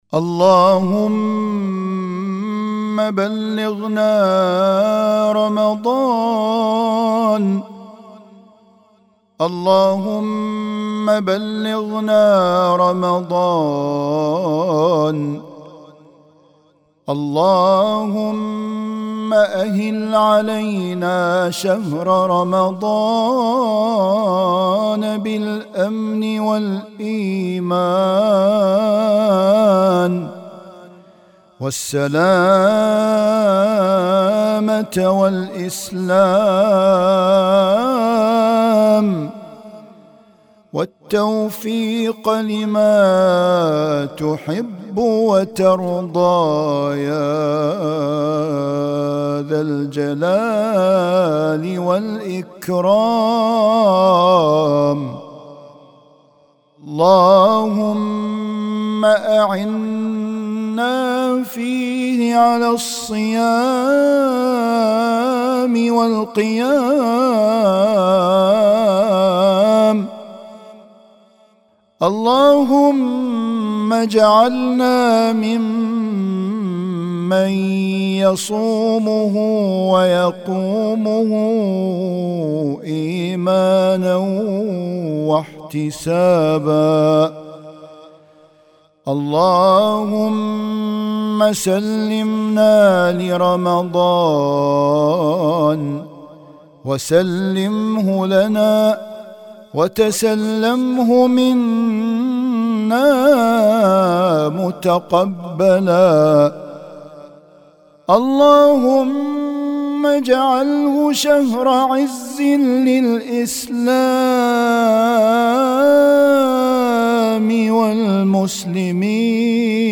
أدعية وأذكار